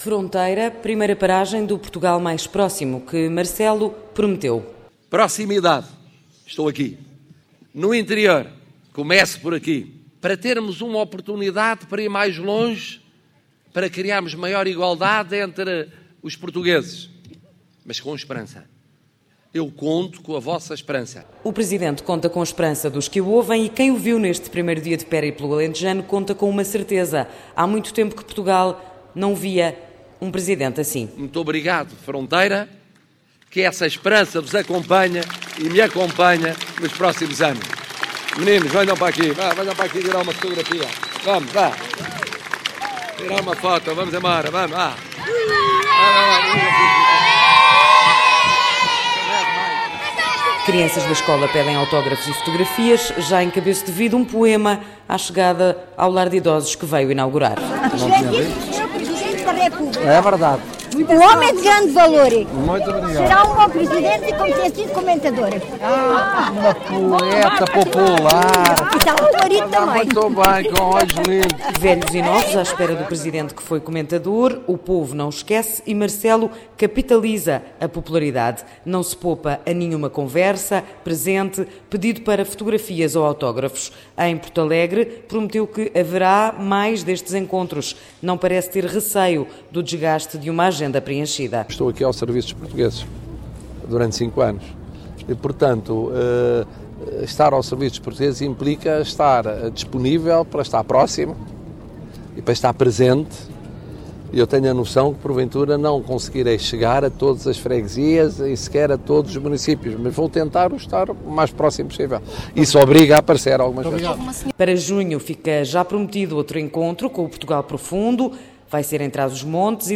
O Presidente da República deu esta quinta-feira, no Alentejo, o pontapé de saída na iniciativa “Portugal Próximo”.